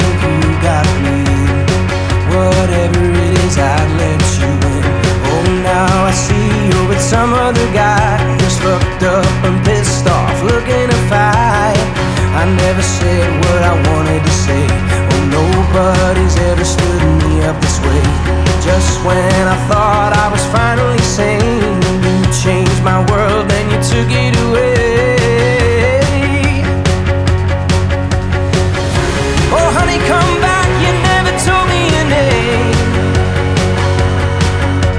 # Singer